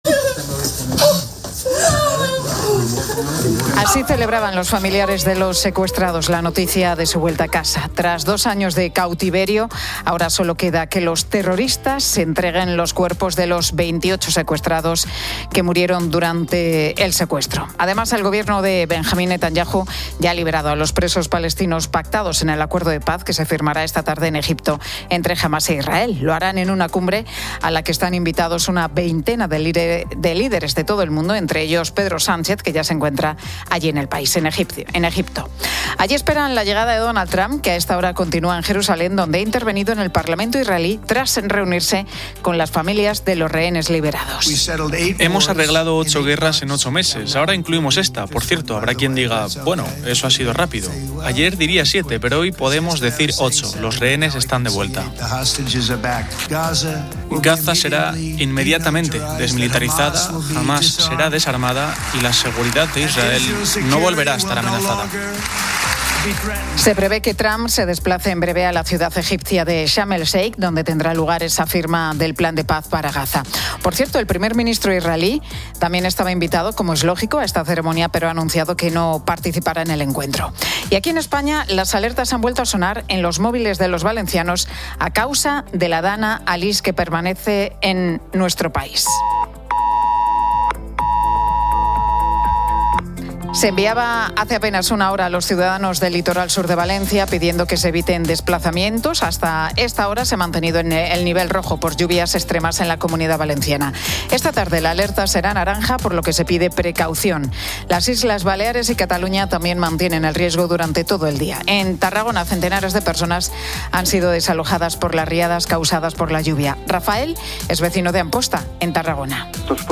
La Tarde 15:00H | 13 OCT 2025 | La Tarde Pilar García Muñiz conecta con las emisoras en las zonas afectadas por la DANA Alice, que sigue causando estragos para que nos cuenten cómo lo están viviendo.